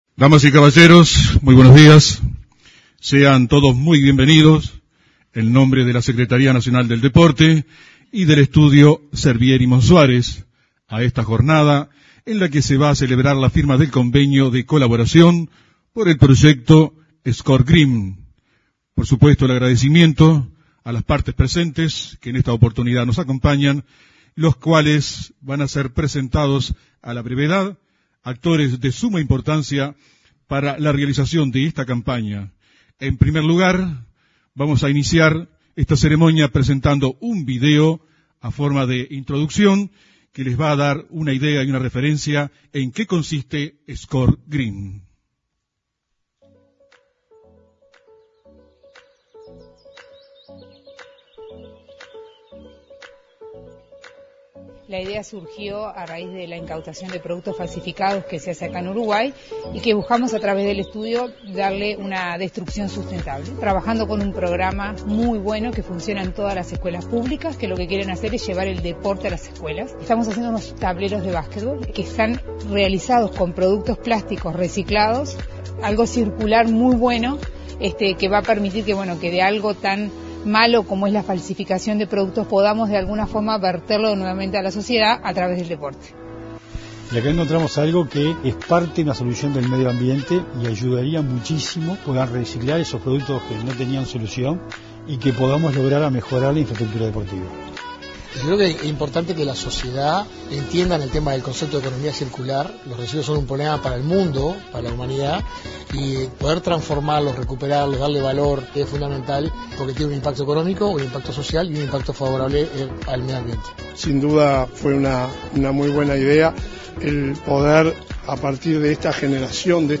Este jueves 28, la Secretaría Nacional del Deporte y el programa Score Green suscribieron un convenio en el salón de actos de la Torre Ejecutiva.